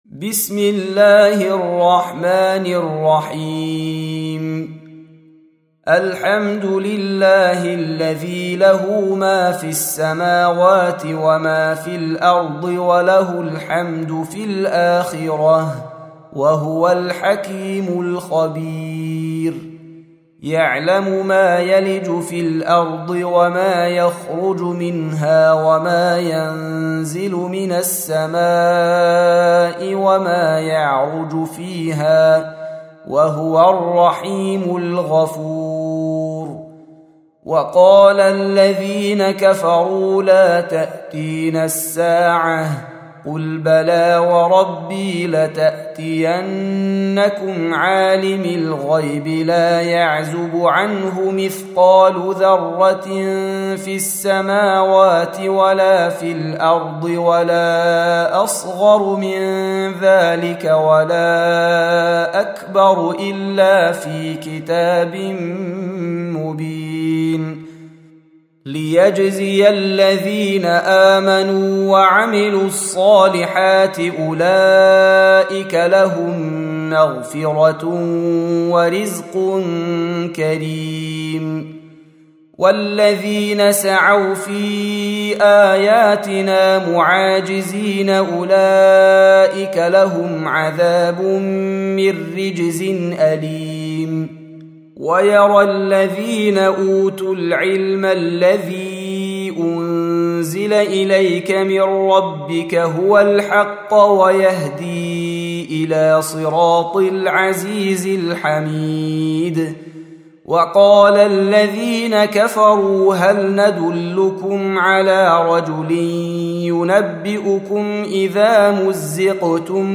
34. Surah Saba' سورة سبأ Audio Quran Tarteel Recitation
Surah Repeating تكرار السورة Download Surah حمّل السورة Reciting Murattalah Audio for 34.